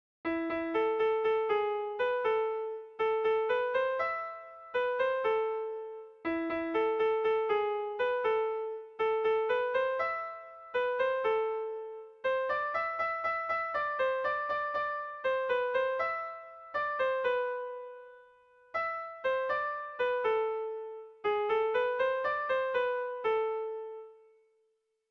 Irrizkoa
Seikoa, berdinaren moldekoa, 5 puntuz (hg) / Bost puntukoa, berdinaren moldekoa (ip)
ABDE